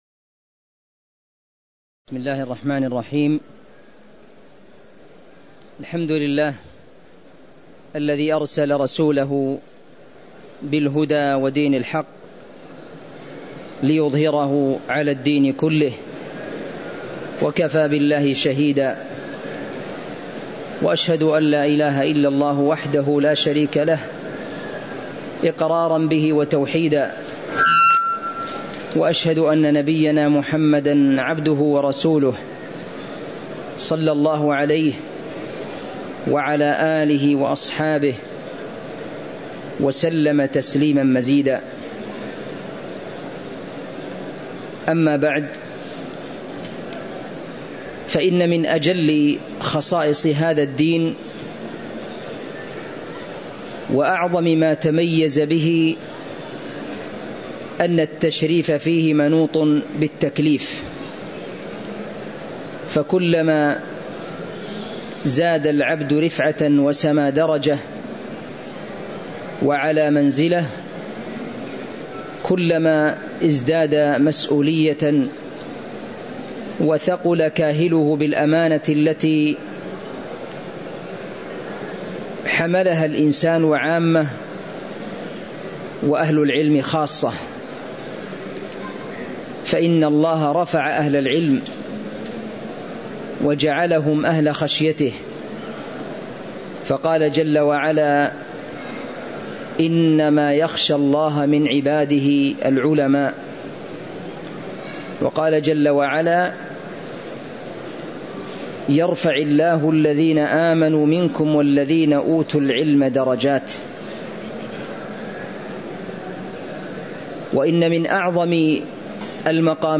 تاريخ النشر ١٧ جمادى الأولى ١٤٤٦ هـ المكان: المسجد النبوي الشيخ